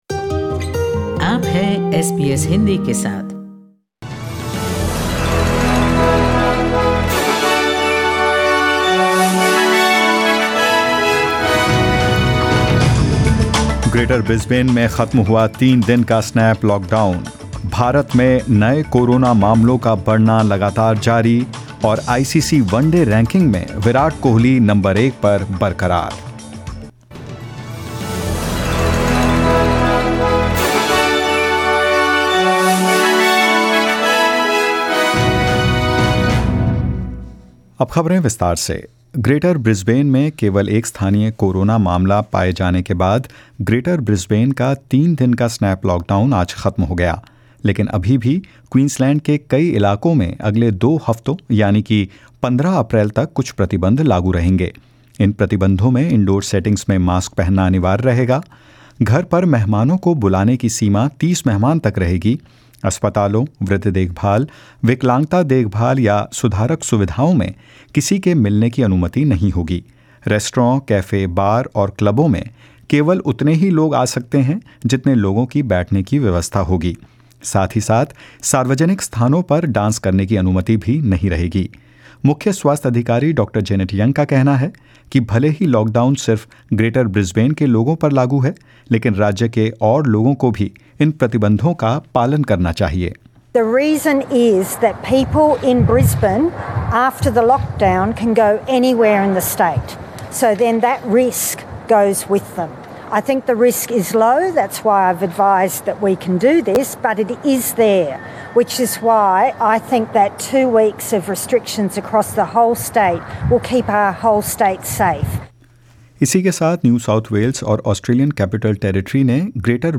In this bulletin : The state and federal government war of words over the pace of the vaccine rollout intensifies.